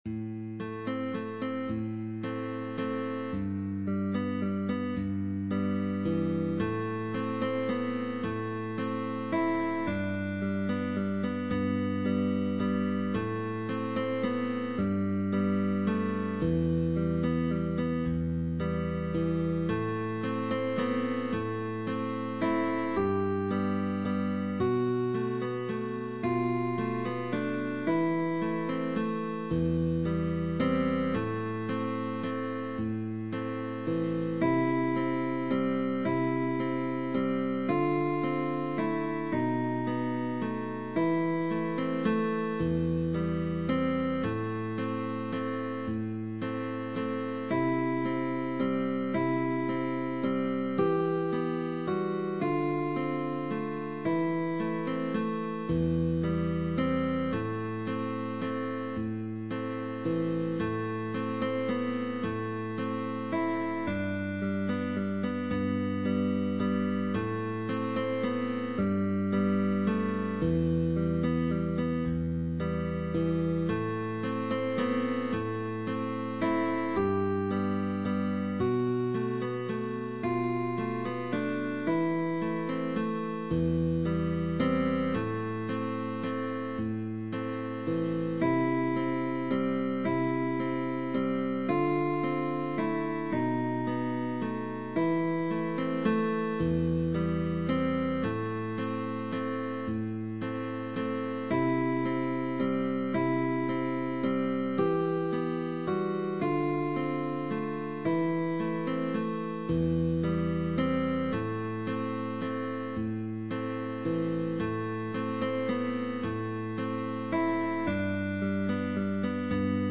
GUITAR DUO (PUPIL & TEACHER)
Guitar Duos